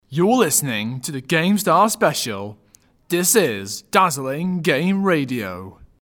Jingle 1 without music